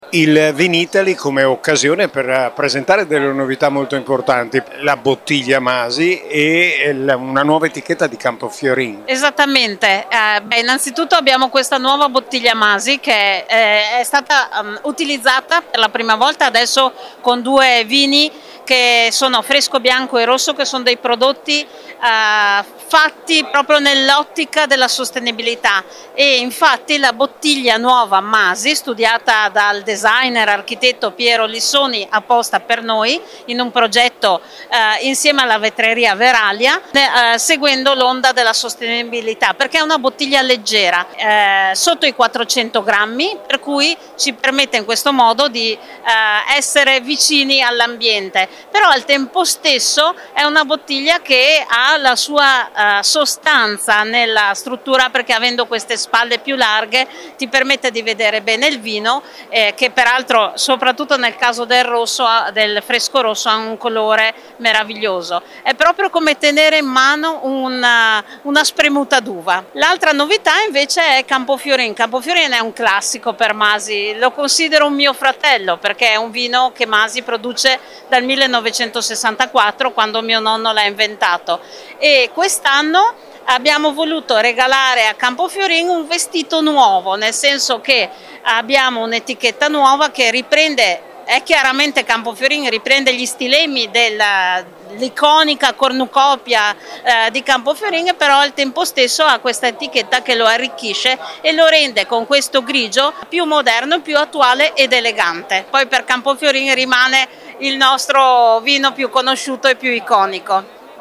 intervistato per noi